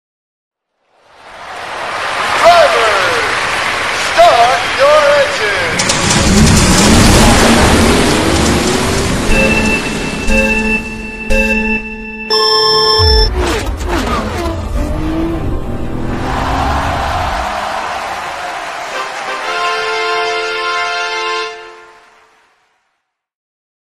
pinewood derby start Meme Sound Effect
pinewood derby start.mp3